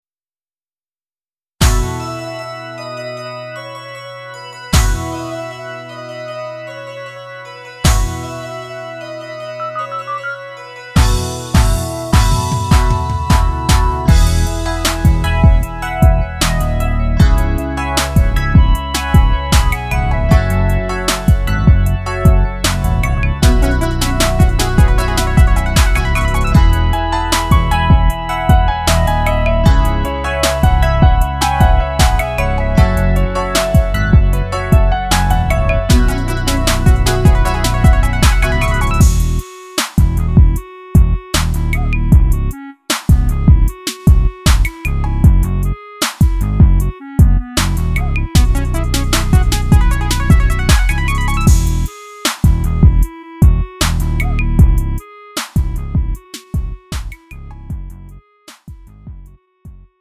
음정 원키 3:25
장르 구분 Lite MR